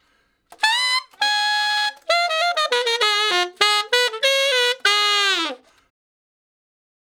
068 Ten Sax Straight (Ab) 11.wav